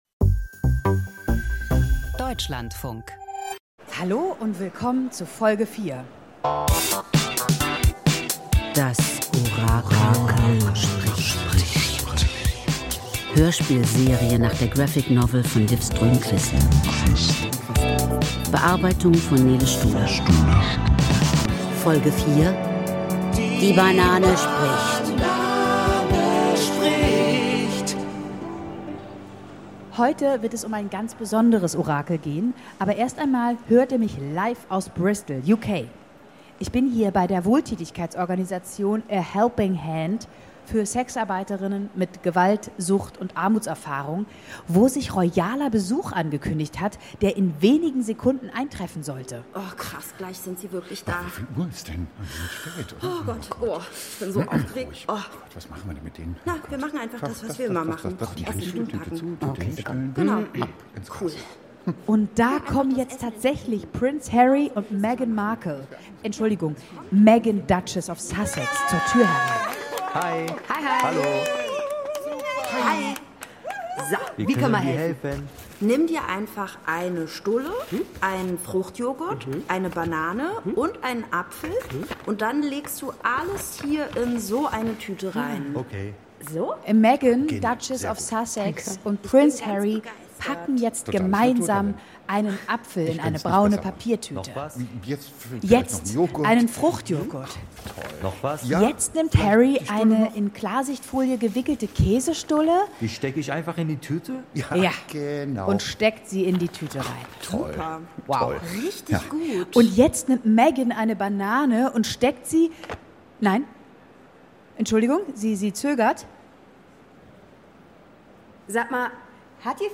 Das Orakel spricht – Hörspielserie nach Liv Strömquist